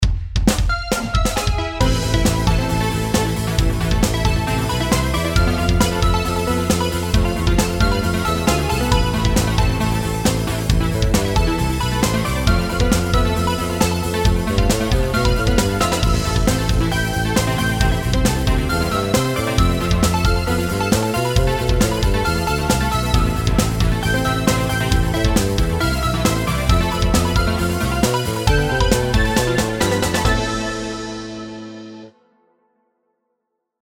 音楽ジャンル： テクノ
楽曲の曲調： MIDIUM
ひっ迫にメロディを付けたBGM